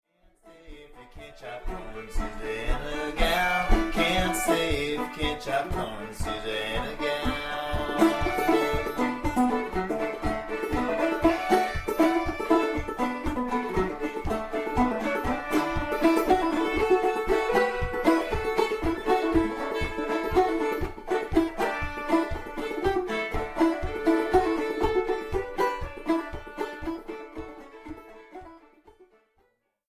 VOCALS / VIOLIN / FIDDLE
Southern songs